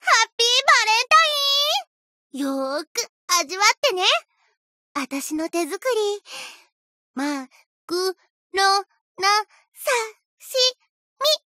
贡献 ） 协议：Copyright，其他分类： 分类:黄金船语音 您不可以覆盖此文件。